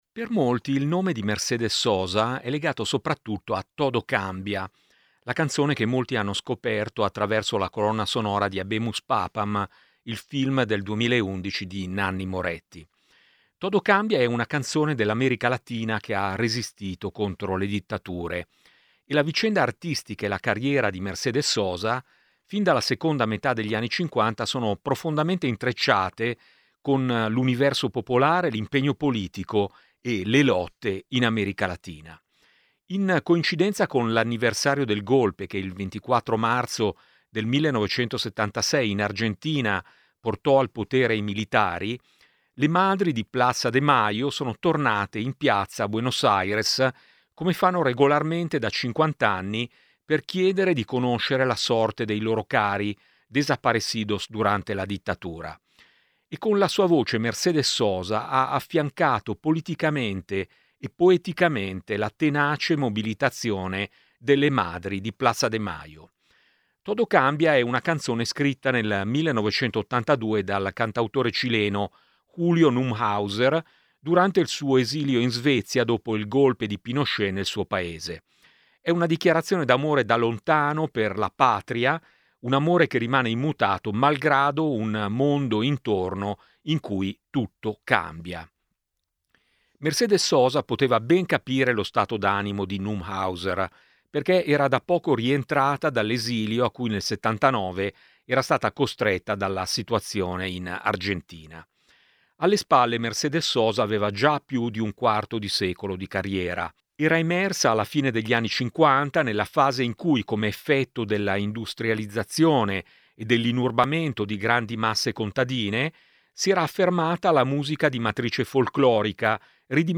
Servizio su Mercedes Sosa di giovedì 26/03/2026